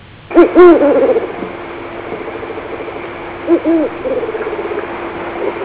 El Tucúquere magallánico (Bubo magellanicus)
Difícil de avistar debido a sus hábitos nocturnos aun cuando fácilmente reconocible por la vocalización que emite que reproduce su nombre.
tucuquere.au